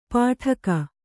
♪ pāṭhaka